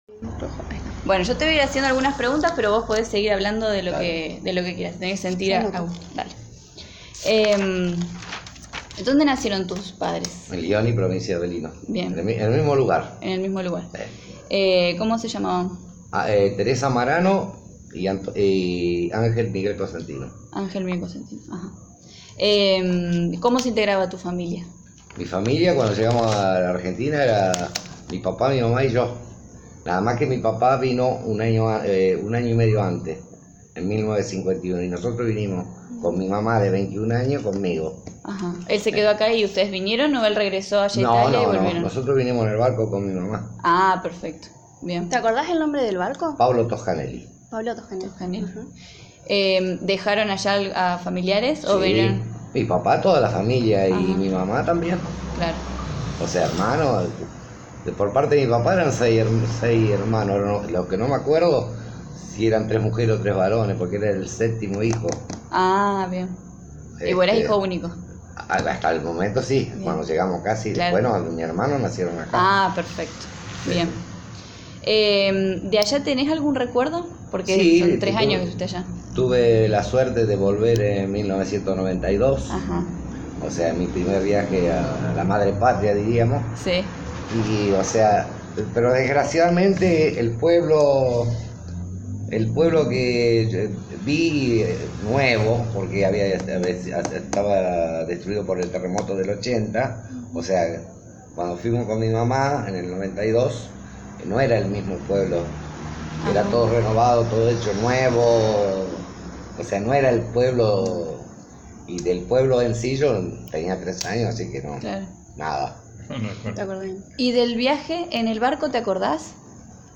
Entrevista realizada el 26 de marzo de 2018.